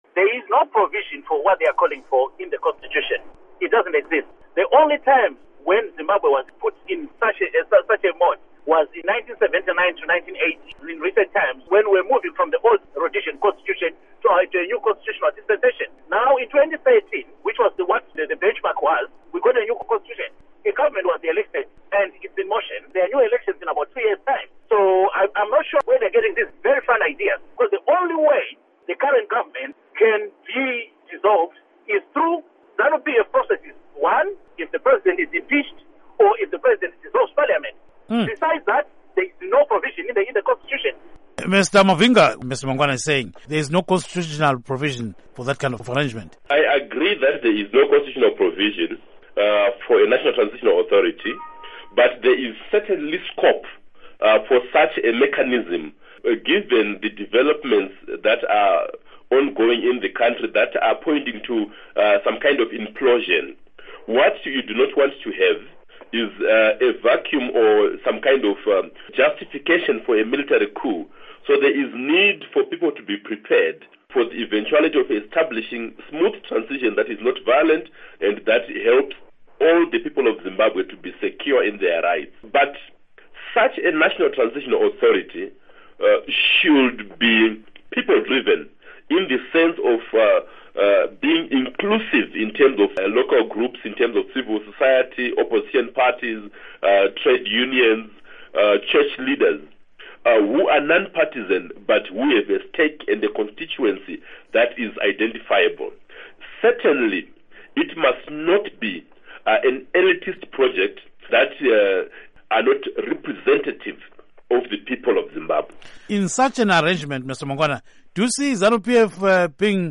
Report On Transitional Government